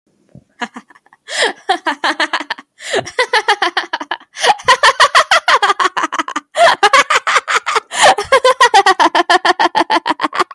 Laughing In A Mic